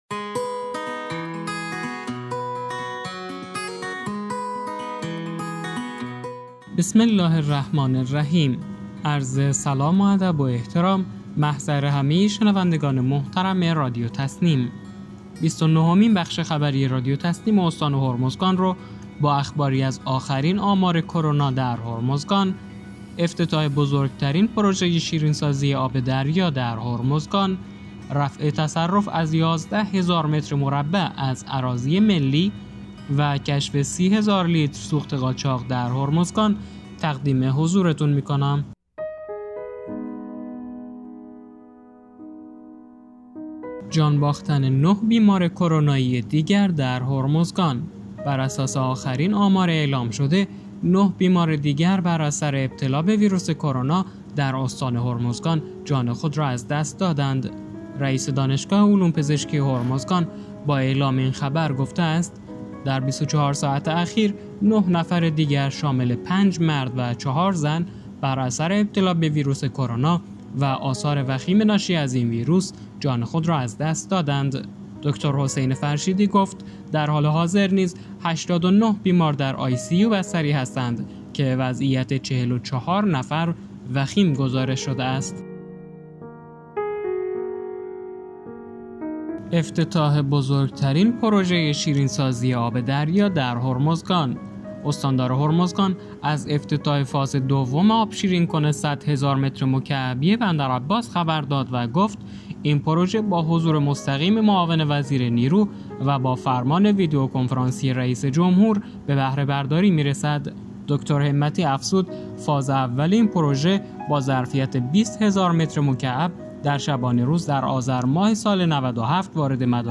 به گزارش خبرگزاری تسنیم از بندرعباس، بیست و نهمین بخش خبری رادیو تسنیم استان هرمزگان با اخباری از آخرین آمار کرونا در هرمزگان، افتتاح بزرگ‌ترین پروژه‌ شیرین‌سازی آب دریا در هرمزگان، رفع تصرف از 11 هزار متر مربع از اراضی ملی و کشف 30هزار لیتر سوخت قاچاق در هرمزگان منتشر شد.